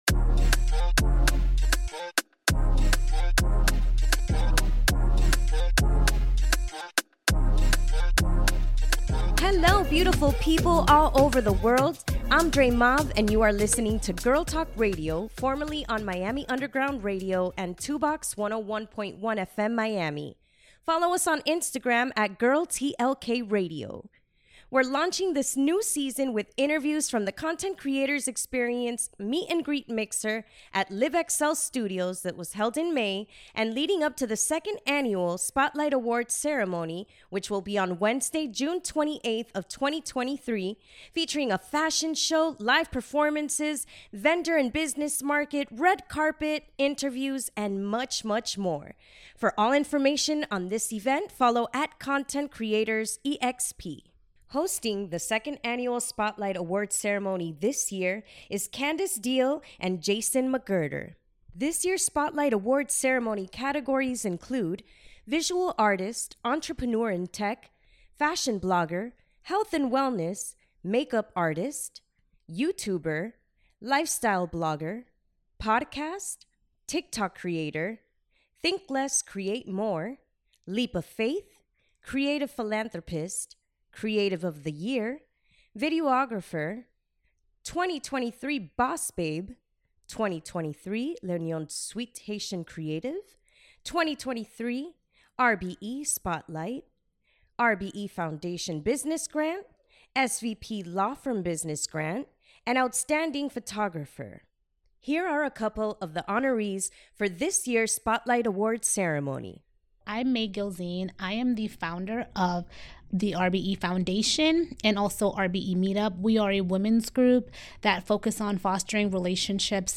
Content Creators Experience: Interviews from the Meet & Greet Mixer at Live XL Studios in preparation of the 2nd Annual Spotlight Award Ceremony 2023